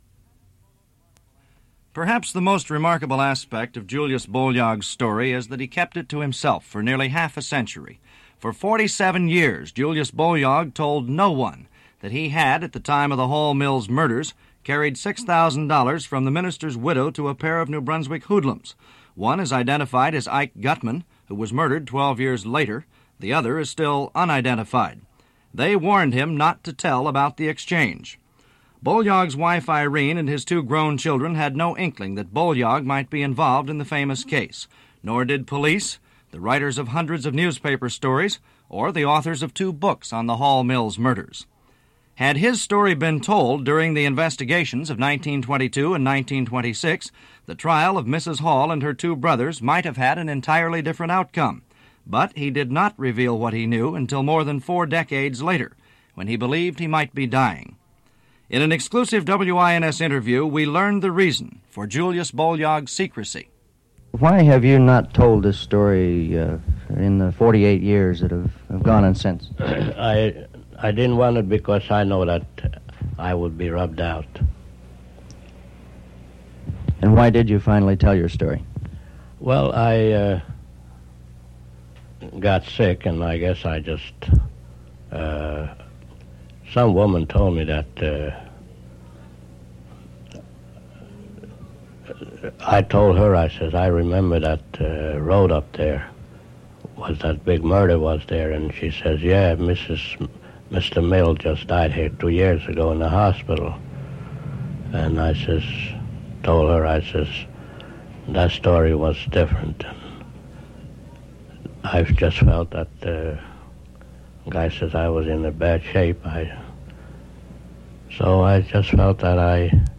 Hall-Mills Murder Radio Broadcasts
It is not known if the audio recording was made at WINS or recorded off the air.